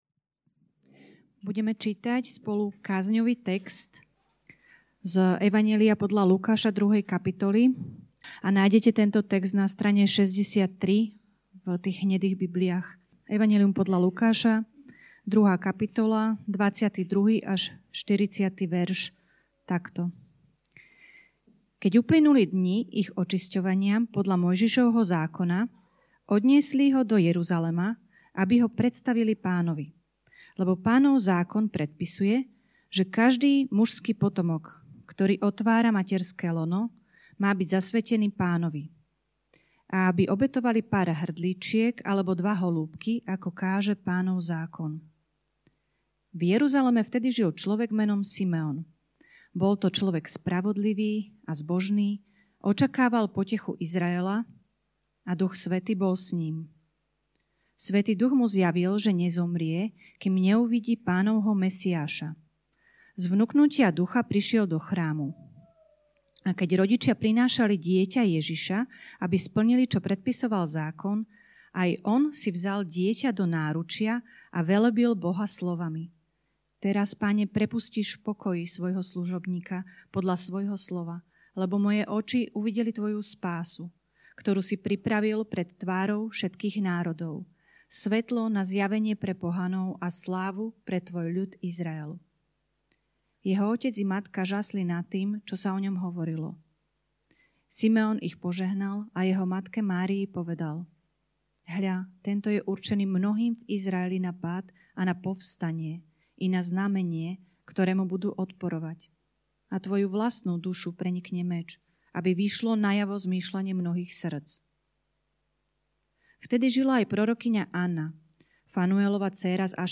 Kázne zboru CB Trnava